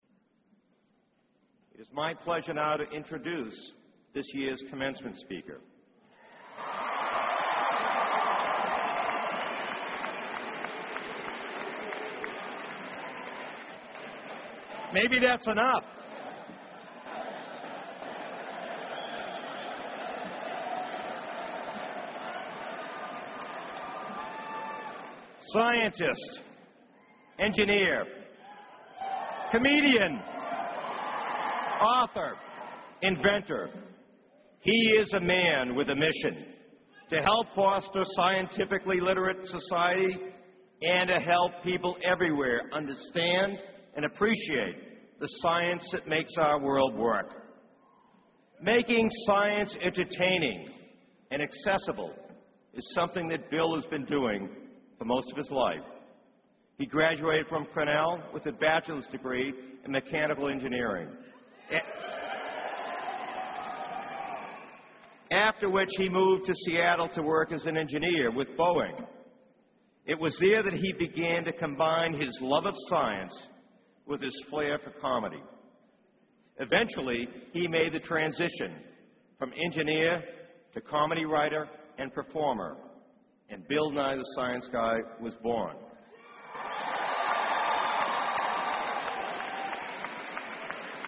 公众人物毕业演讲 第154期:比尔·奈马萨诸塞大学2014(1) 听力文件下载—在线英语听力室